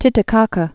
(tĭtĭ-käkə, tētē-käkä), Lake